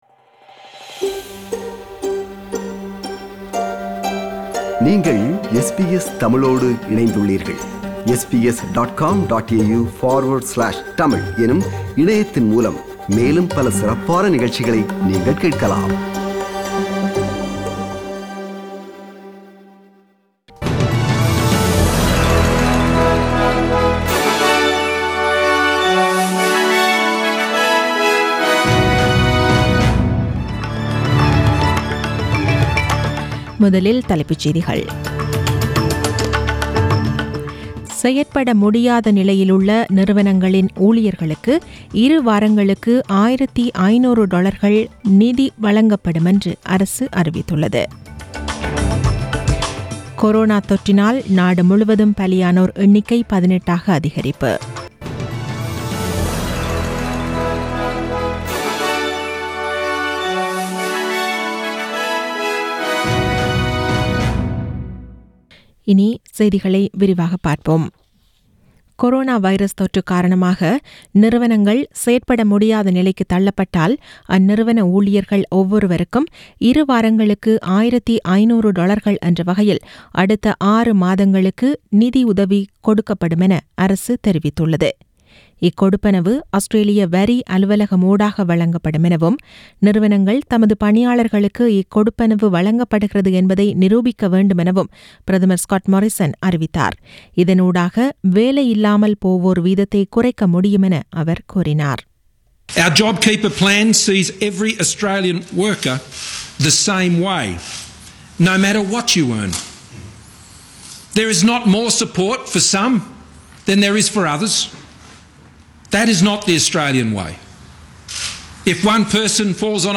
The news bulletin was aired on 30 March 2020 (Monday) at 8pm.